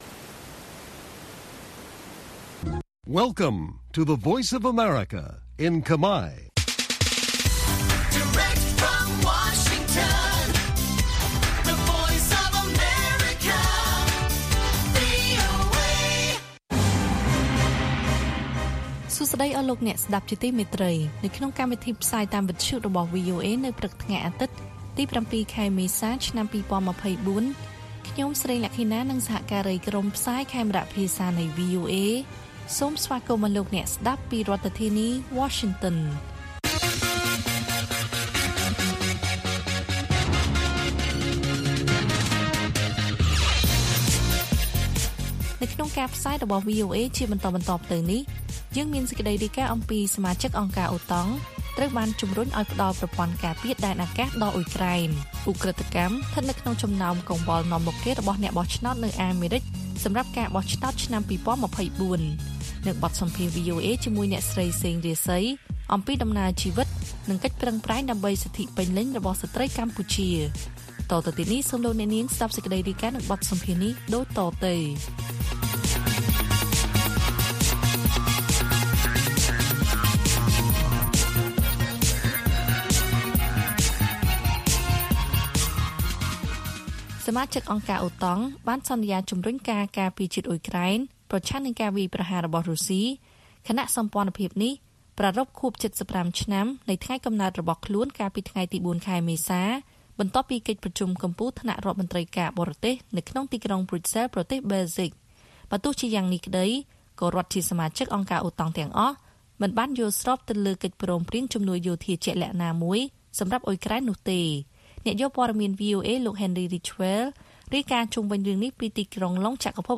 ព័ត៌មានពេលព្រឹក ៧ មេសា៖ សមាជិកអង្គការអូតង់ត្រូវបានជំរុញឱ្យផ្តល់ប្រព័ន្ធការពារដែនអាកាសដល់អ៊ុយក្រែន